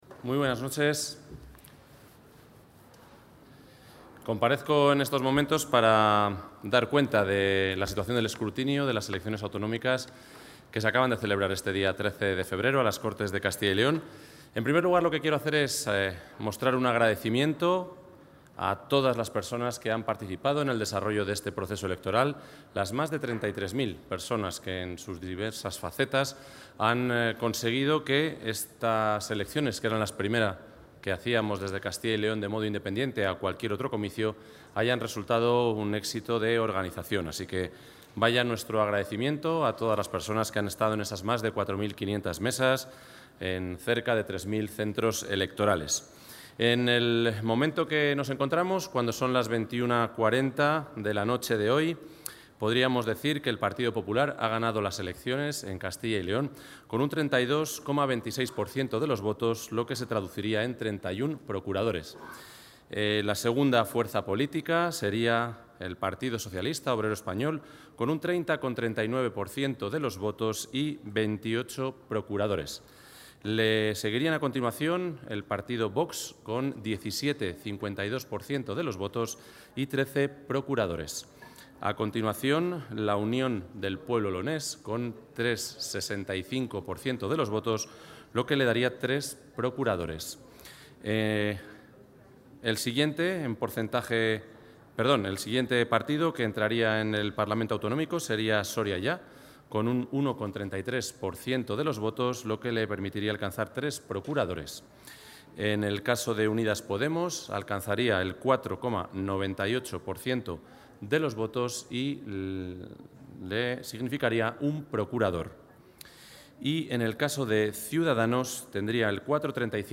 Rueda de prensa.
El consejero de la Presidencia, Ángel Ibáñez, ha informado en rueda de prensa sobre el resultado de las elecciones autonómicas.